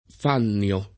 Fannio [ f # nn L o ]